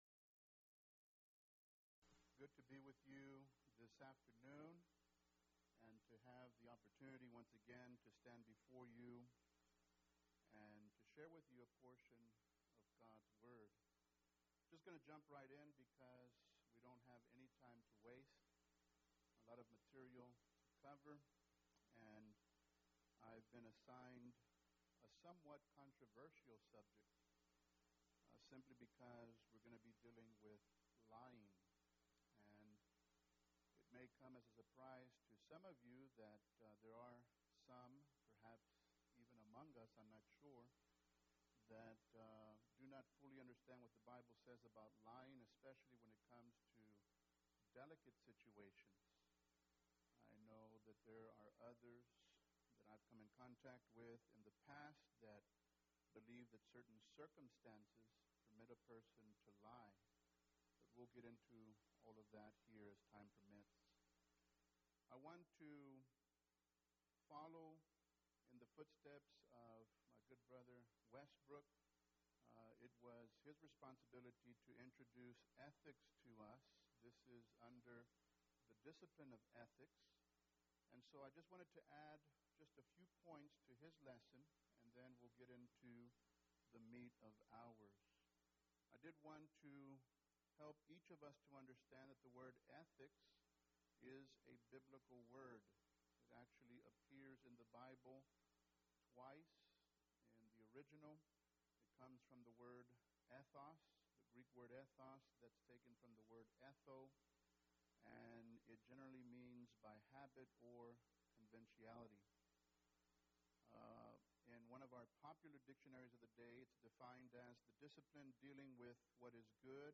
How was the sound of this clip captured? Event: 4th Annual Men's Development Conference